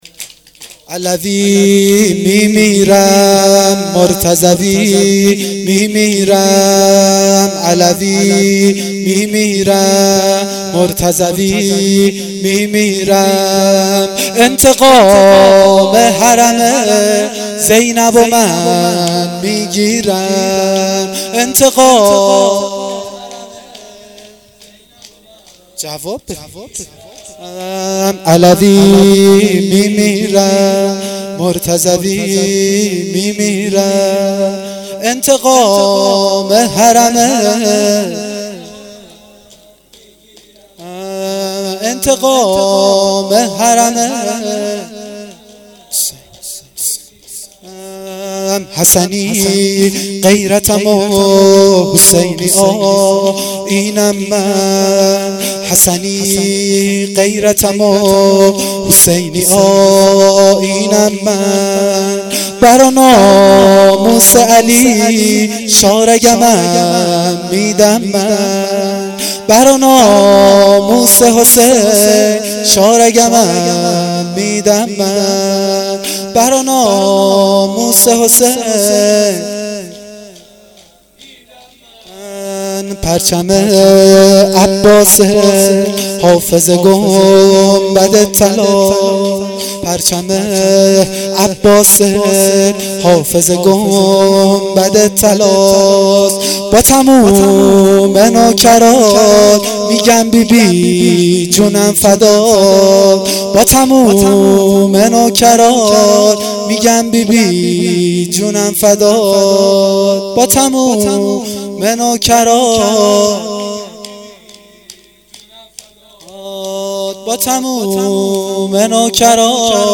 شور وفات حضرت زینب(س)-هیئت هفتگی -هیئت جوادالائمه شهرستان کمیجان.mp3